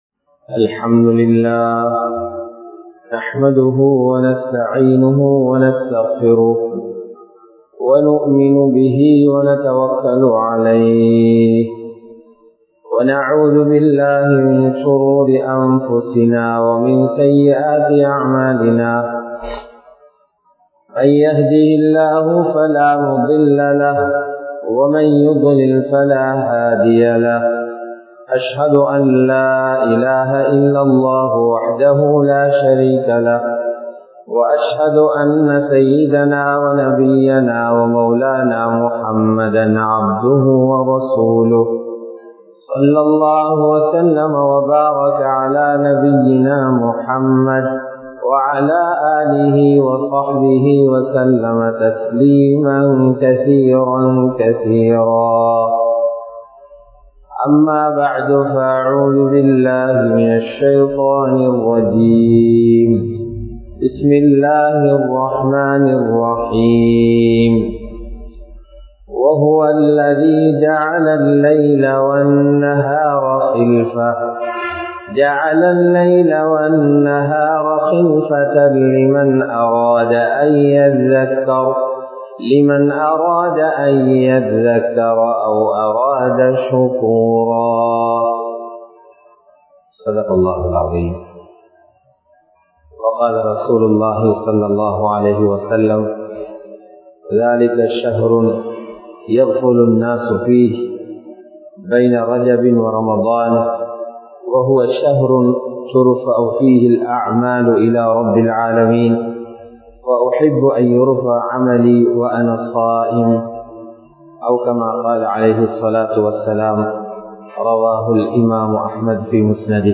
ரமழானுக்குத் தயாராகுவோம் | Audio Bayans | All Ceylon Muslim Youth Community | Addalaichenai
Muhiyaddeen Grand Jumua Masjith